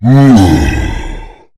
spawners_mobs_balrog_attack.2.ogg